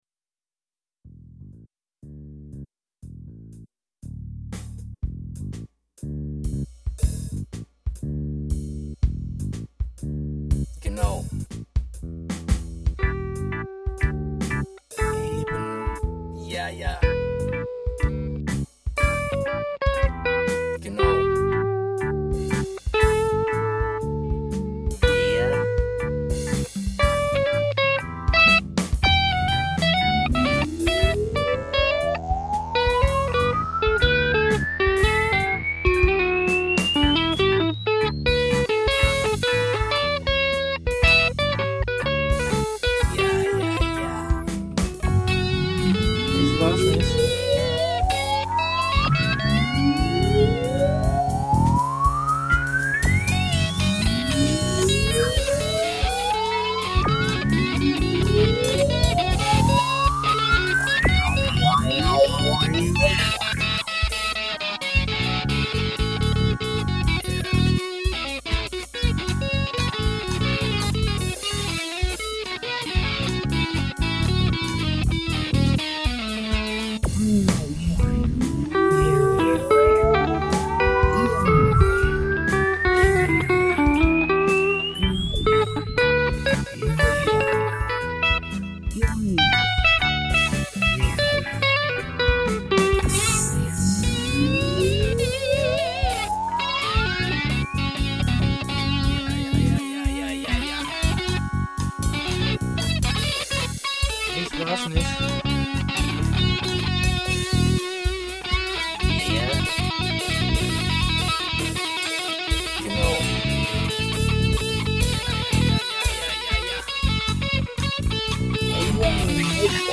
Die Solo-Gitarrenspuren sind mit dem Mittelfinger der rechten Hand gezupft, weil das zu dem Zeitpunkt, das einzige war, was ich am rechten Arm noch einigermassen kontrollieren konnte.
Genau hat wenigstens einen (wenn auch nur rudimentären) Text.